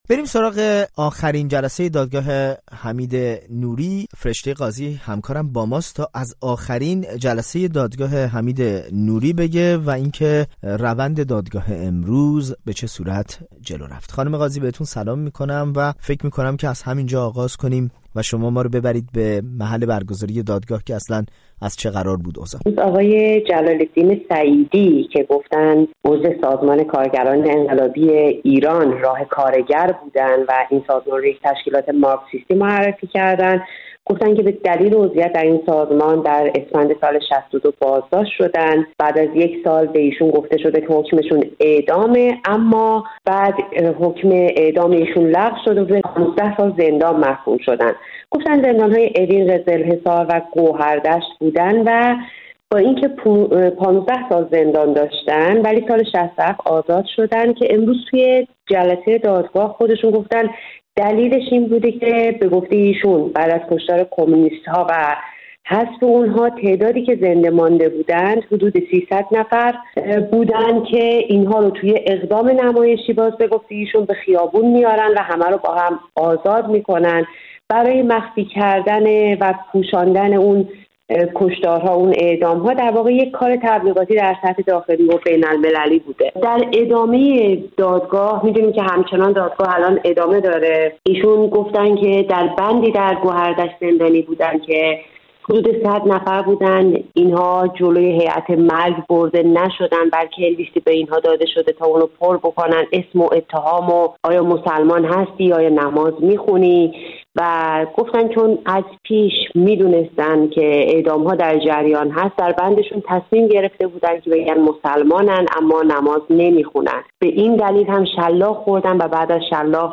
شهادت